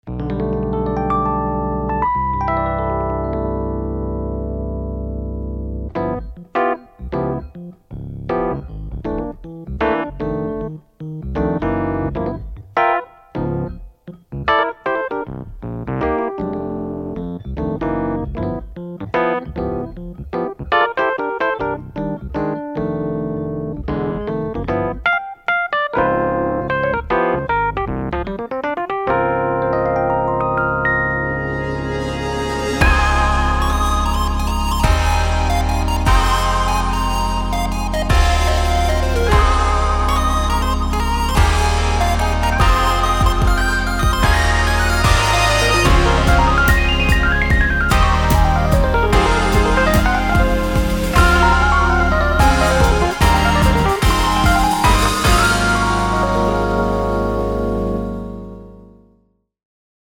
LEGENDARY ELECTRIC PIANOS
• Ikonische Vintage-Sounds von Rhodes- und Wurlitzer-Pianos
• Kombiniere ausgewählte Mikros und Cabinets mit dem trockenen Direktsignal
Die Sammlung liefert ein klassisches Suitcase-Modell und ein warm klingendes, Zungen-betontes E-Piano – aufgenommen mit verschiedenen Mikro-Positionen und Cabinets.
01_-_Suitcase_73_-_Demo.mp3